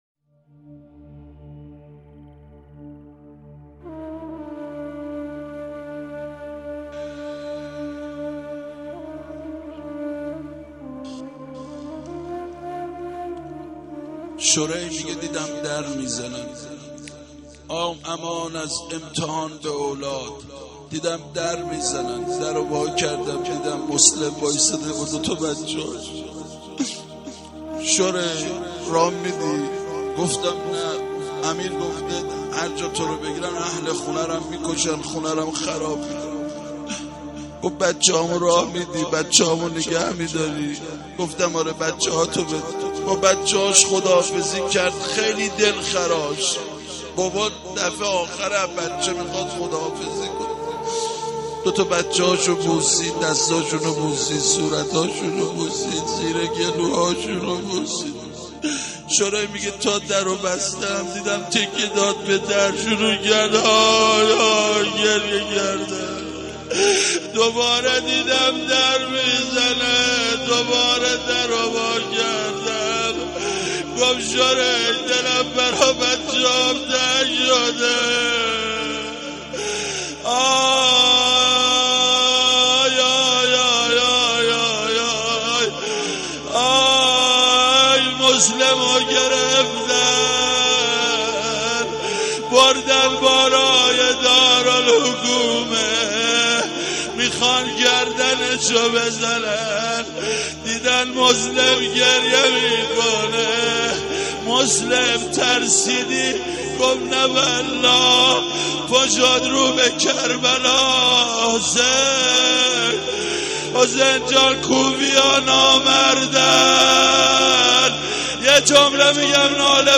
روضه جانسوز حضرت مسلم(علیه السلام)